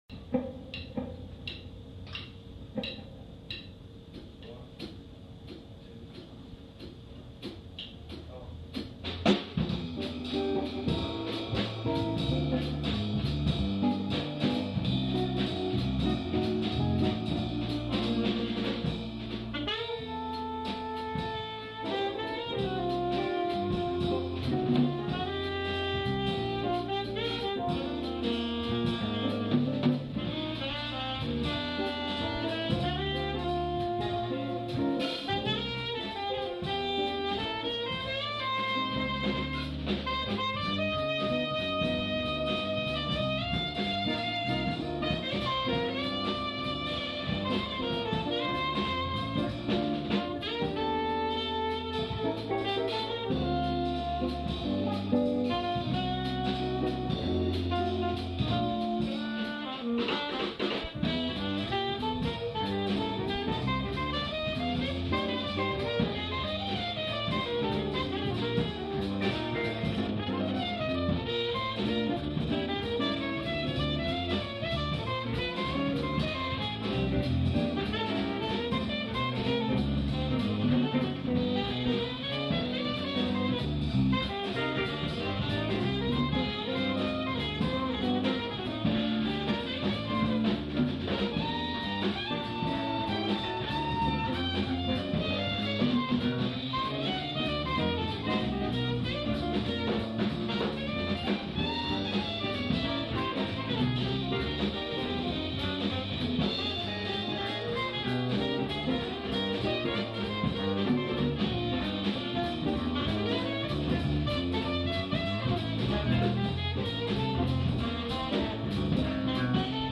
Electric Bassist in Los Angeles For Approximately Four Years
Jeff Kashiwa quartet rehearsal before gig at Long Beach City College playing "Stella By Starlight"